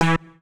Universal UI SFX / Clicks
UIClick_Simple Button2 01.wav